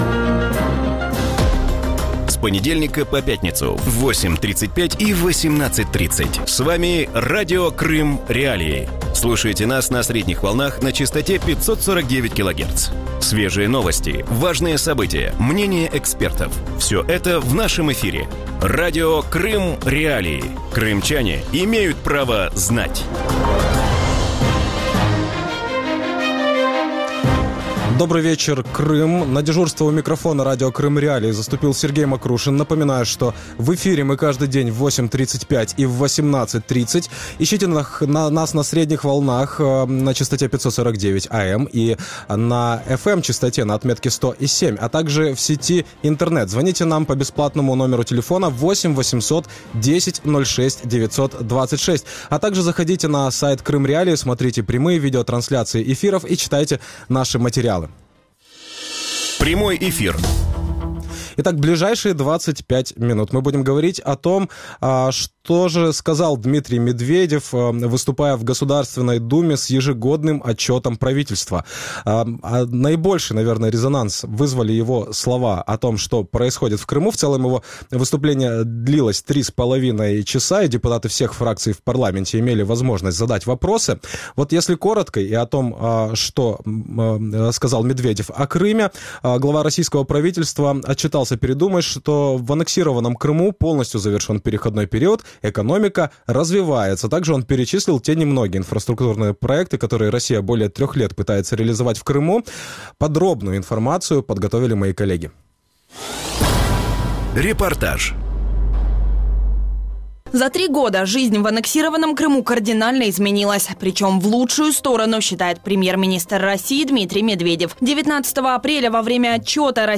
В вечернем эфире Радио Крым.Реалии обсуждают ежегодный отчет российского премьер-министра Дмитрия Медведева, в котором упоминались успехи и в Крыму.